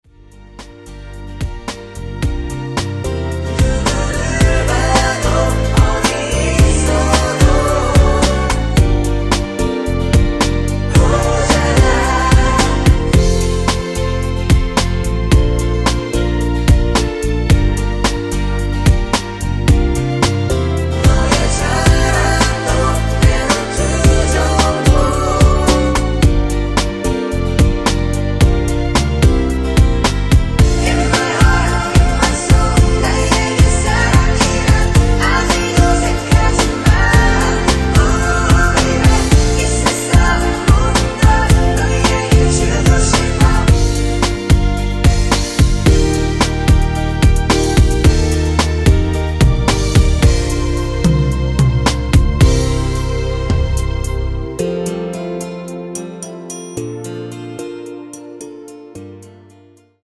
원키에서(+2)올린 코러스 포함된 MR입니다.
Eb
앞부분30초, 뒷부분30초씩 편집해서 올려 드리고 있습니다.
중간에 음이 끈어지고 다시 나오는 이유는